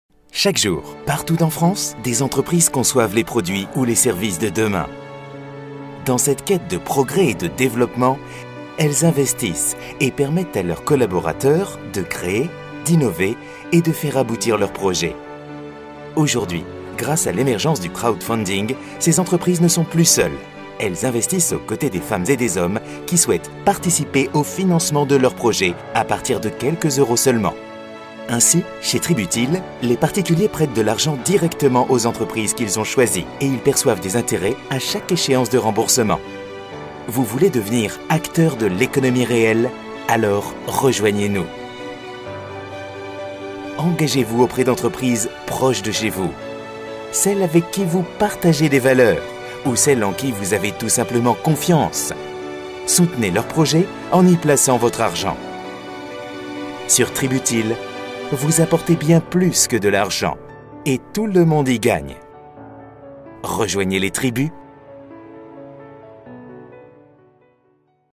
Sprechprobe: Industrie (Muttersprache):
French native (no accent) middle age male voice-talent since 1988, i have my own recording facilities and deliver in 2 to 6 hours ready to use wavs/mp3 files, paypal accepted, my voice is clear sounding serious but friendly at the same time !